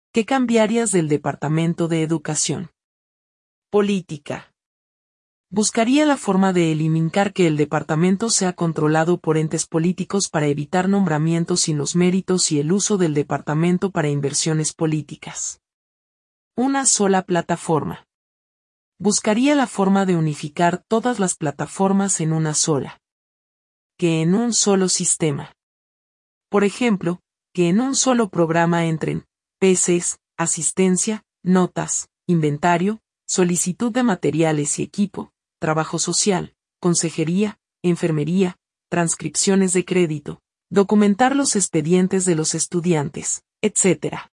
Una maestra presenta un testimonio sobre lo que cambiaría del sistema publico educativo de la isla de Puerto Rico.